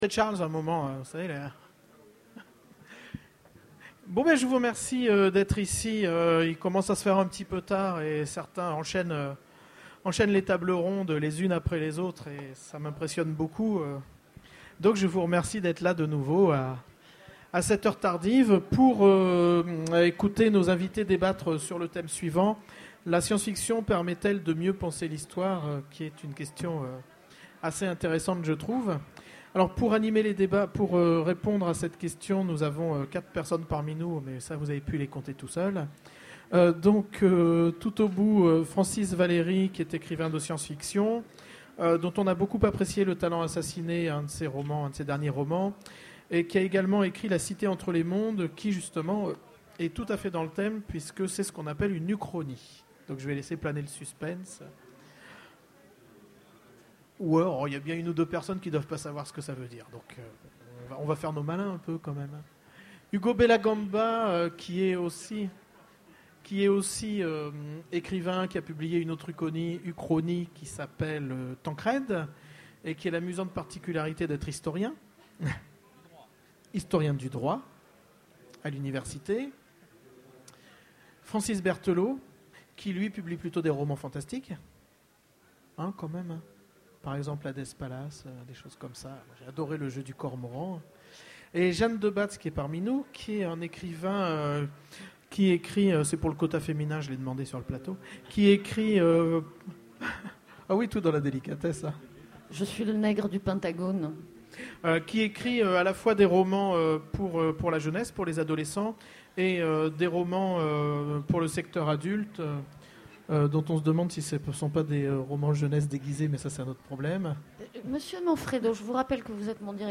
Utopiales 2011 : Conférence La SF permet-elle de mieux penser l'histoire ?